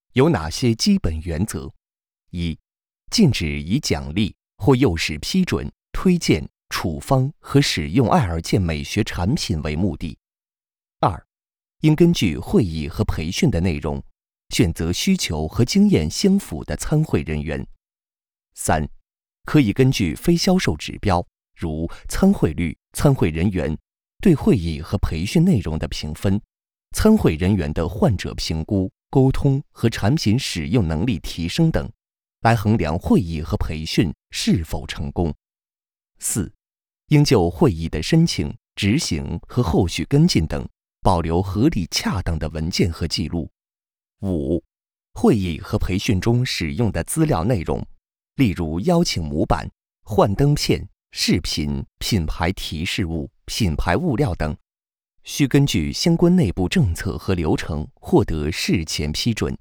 Chinese_Male_005VoiceArtist_20Hours_High_Quality_Voice_Dataset